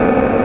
RbtRepeaterAmbientC.wav